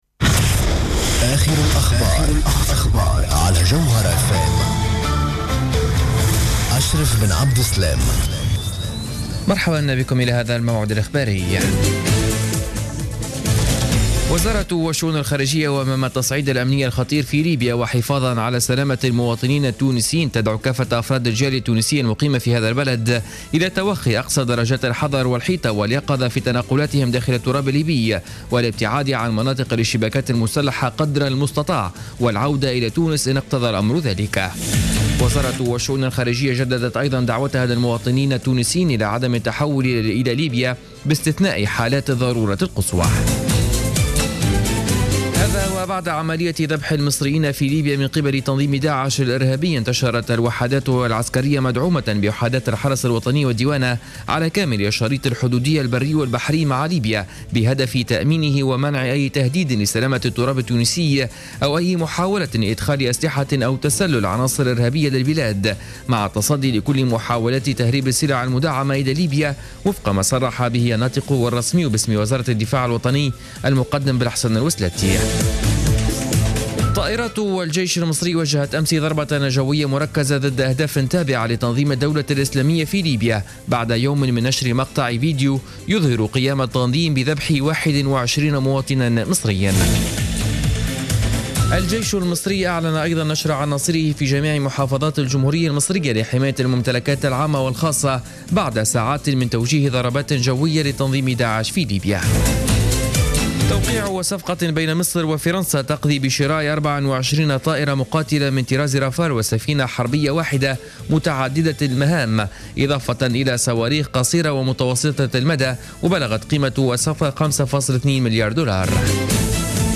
نشرة اخبار منتصف الليل ليوم الثلاثاء 17 فيفري 2015